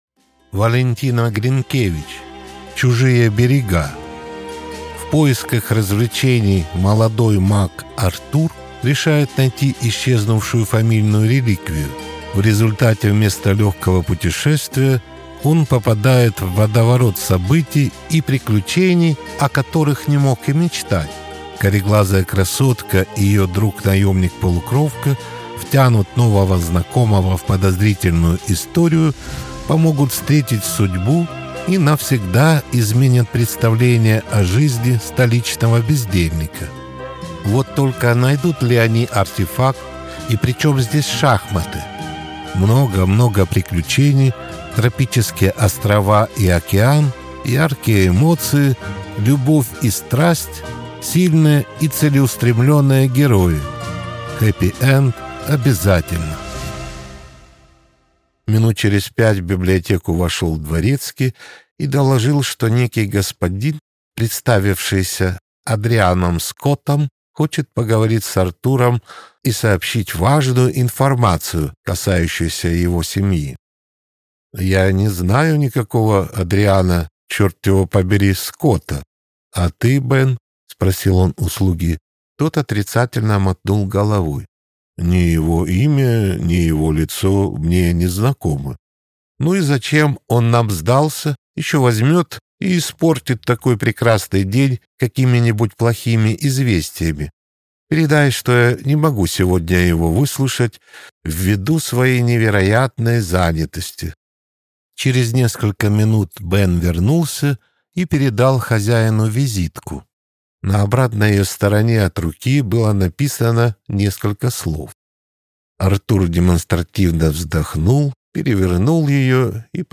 Аудиокнига Чужие берега | Библиотека аудиокниг